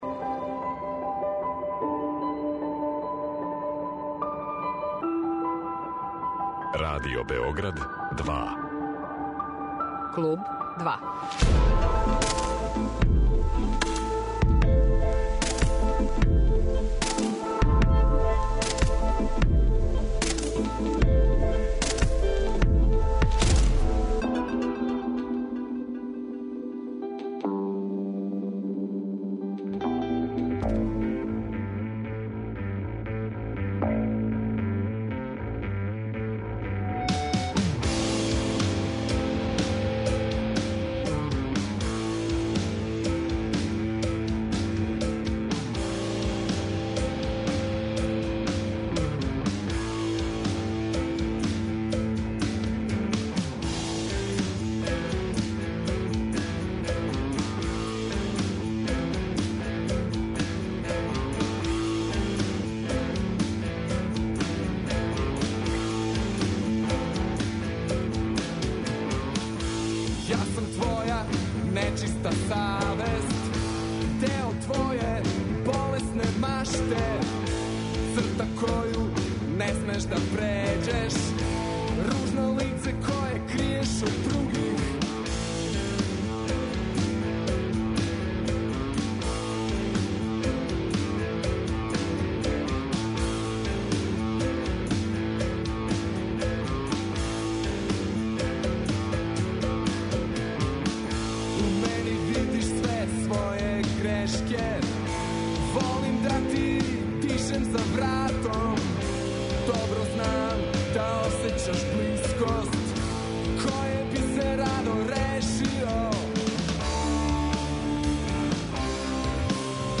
Реч је о нишком инди рок саставу Прљаве сестре. За собом имају један албум објављен пре скоро пет година, са новим снимцима бележе озбиљне помаке ка модернијим инди формама са елементима поп експеримента и психоделије.